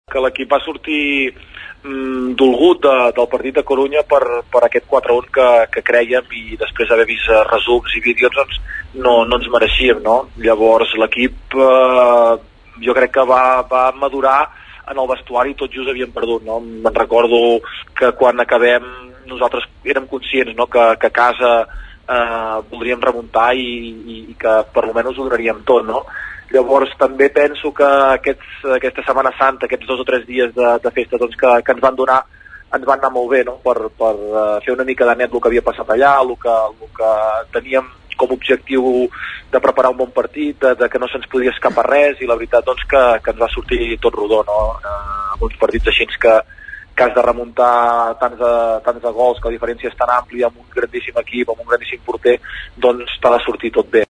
Ell mateix en declaracions al programa “En Joc” de la Xarxa de Comunicació Local valorava la fita aconseguida.